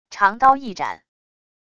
长刀一斩wav音频